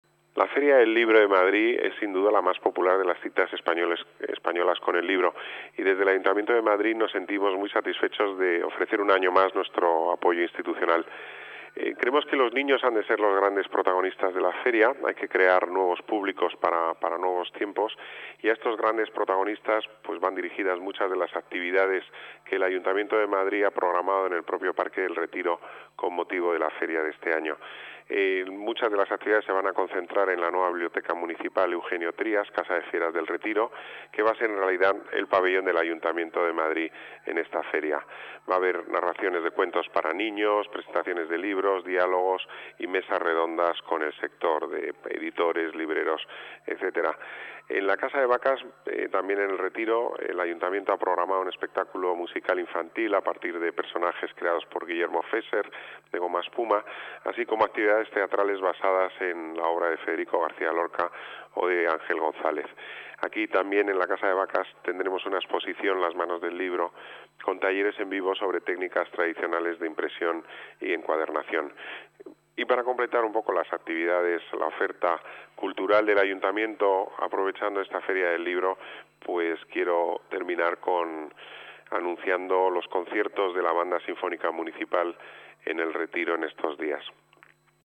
Nueva ventana:Declaraciones del delegado del Las Artes, Deportes y Turismo, Pedro Corral